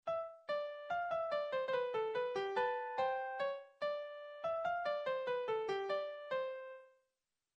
転調のあとチェロとピアノの掛け合いでもう一度技巧的な経過部があり、これで提示部は終わりかと思われる所でまた新たな主題があらわれて